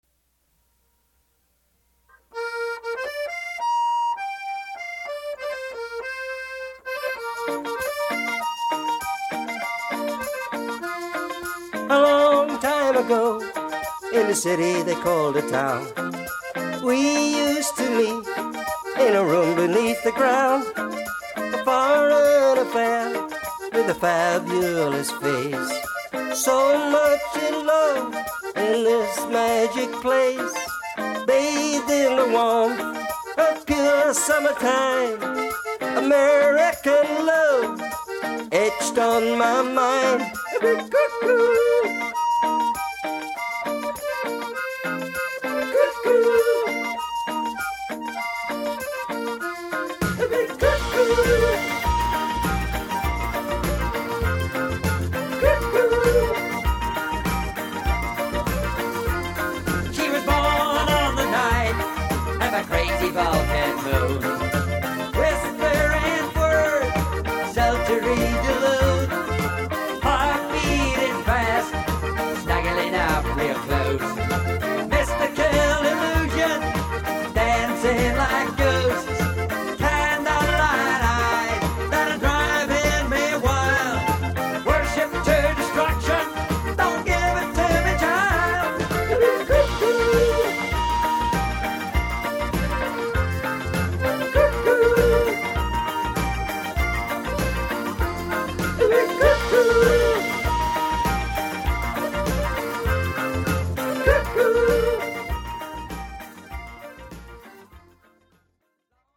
the silly and quirky